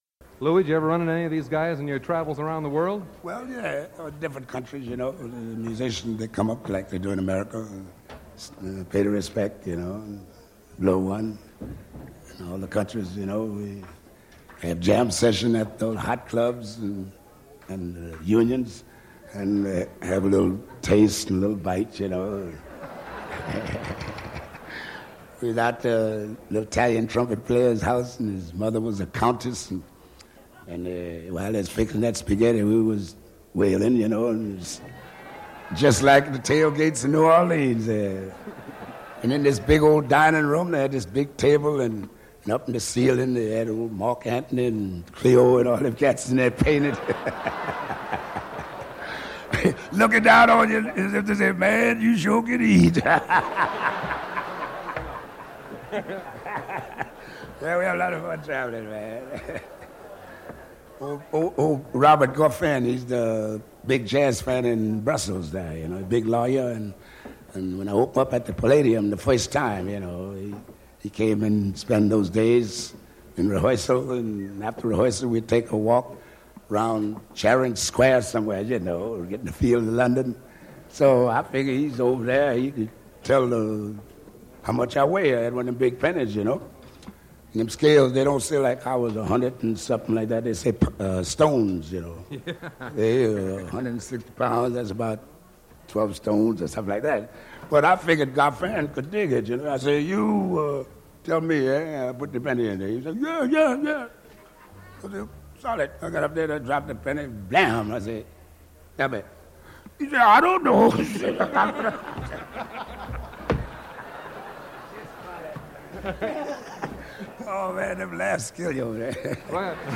Interview with Louis Armstrong 1958
interview with Louis Armstrong